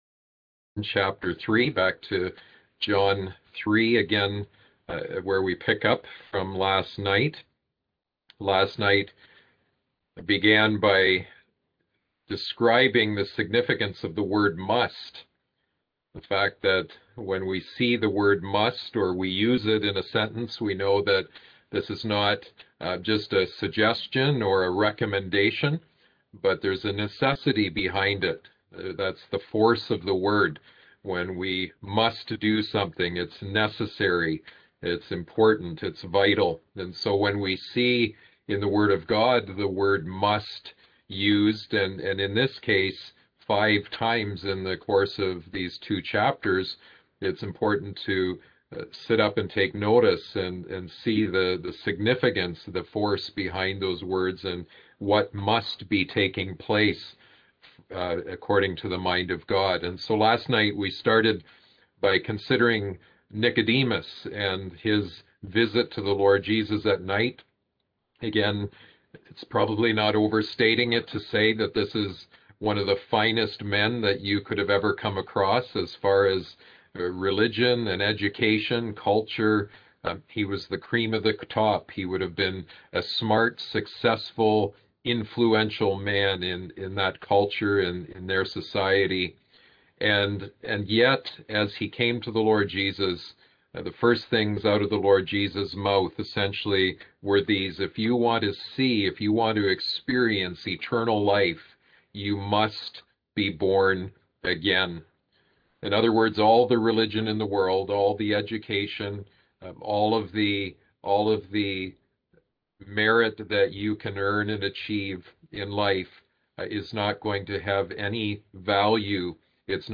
Passage: John 3 Service Type: Seminar